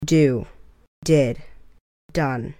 Рядом я подготовила произношение и перевод.